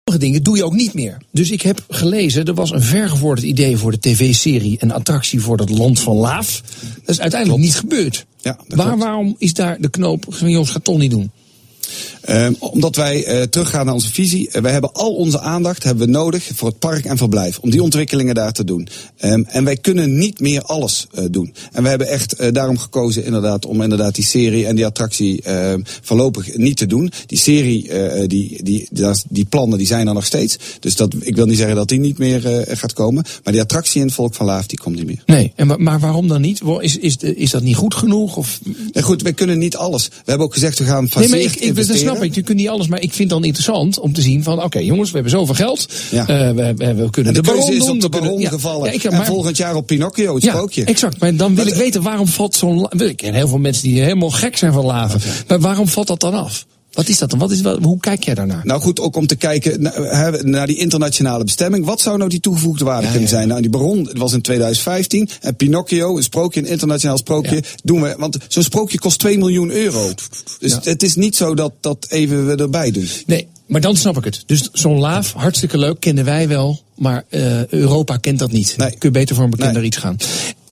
un remix du thème musical